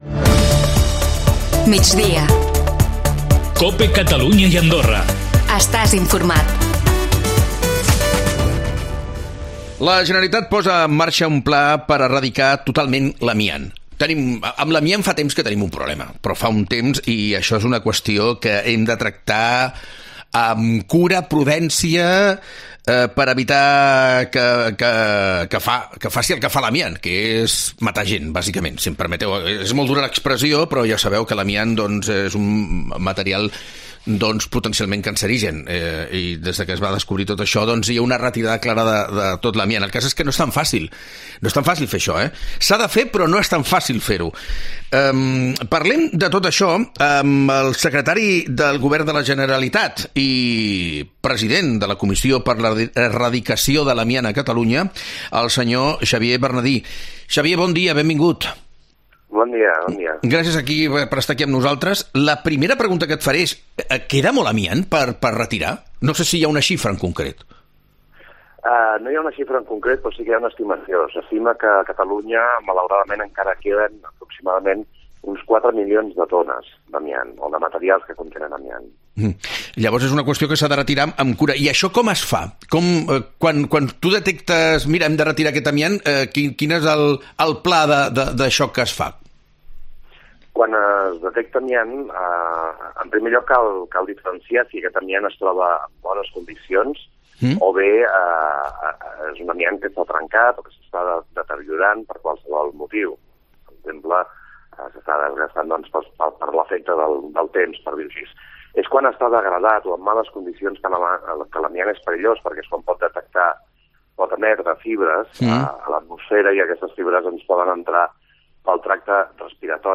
La Generalitat posa en marxa un pla per erradicar totalment l'amiant. Parlem amb el secretari del govern de la Generalitat i president de la comissió per l'Erradicació de l'Amiant de Catalunya, Xavier Bernadí.